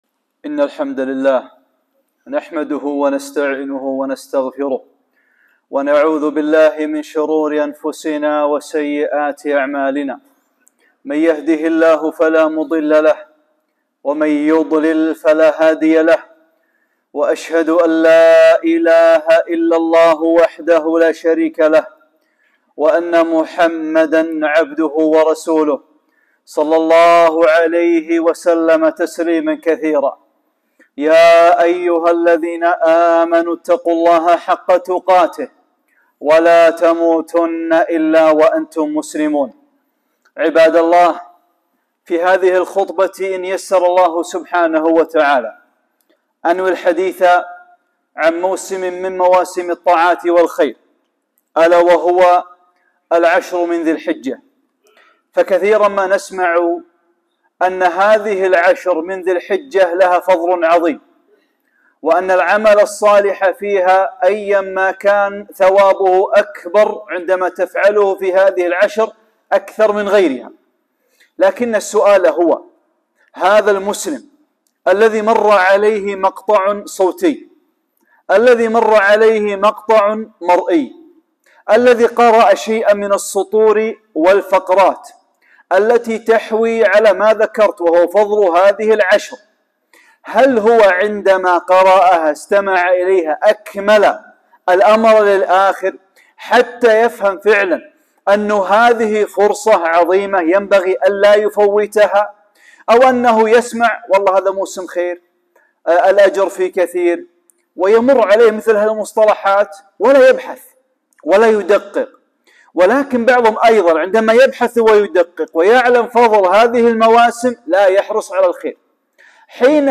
خطبة - فضل العشر من ذي الحجة